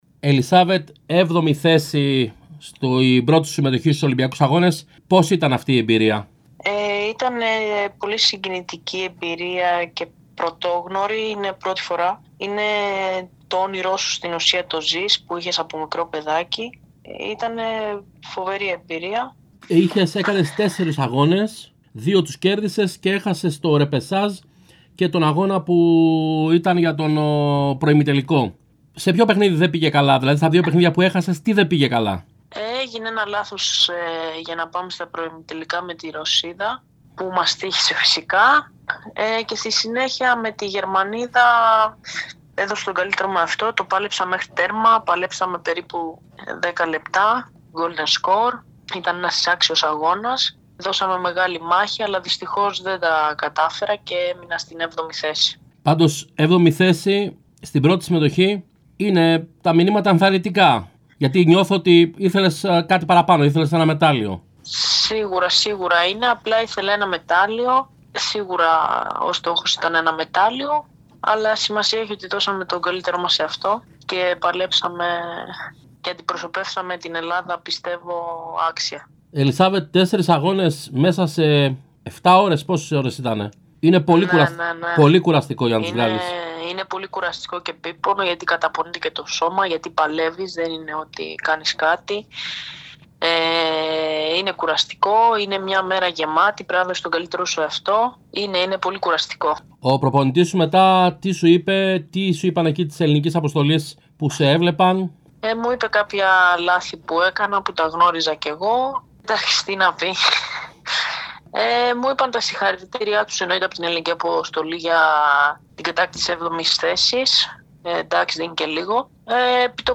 Η 25χρονή τζουντόκα μίλησε στον Realfm 97,8 και στην εκπομπή RealSports